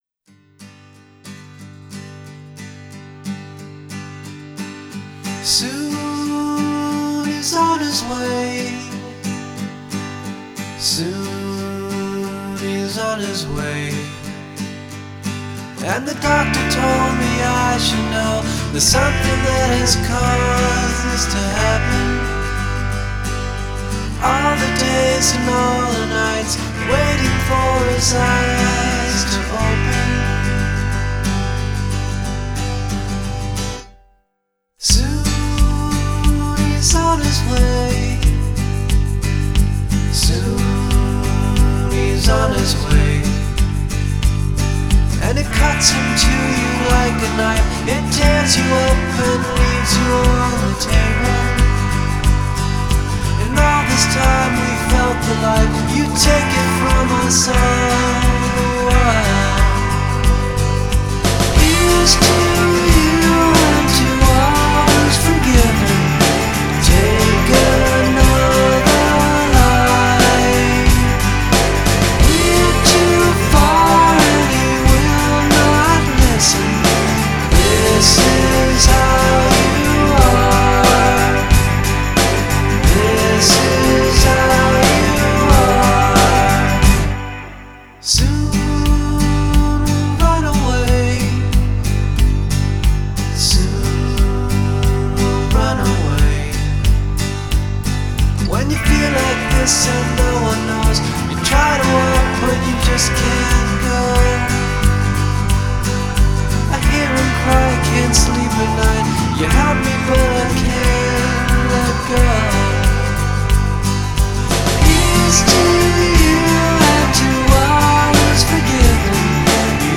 are delightful low key pop excursions.
has a great total sonic ambience, mellow but unrelenting.